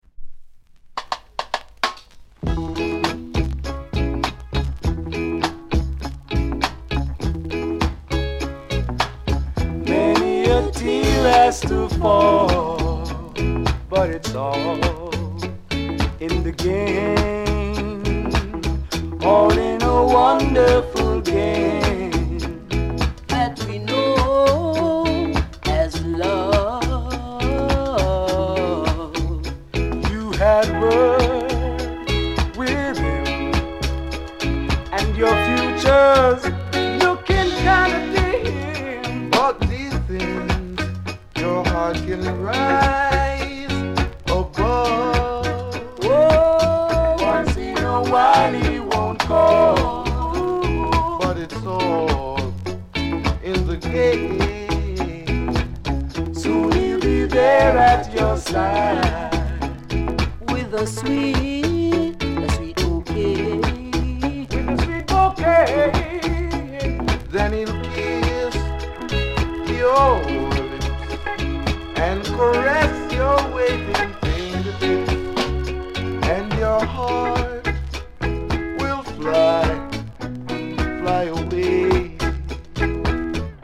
類別 雷鬼